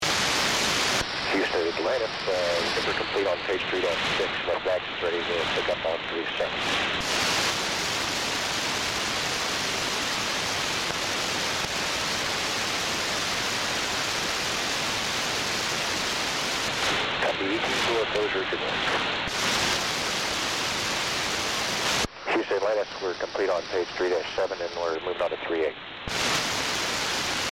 Audio recording: ascending first orbit (over Italy):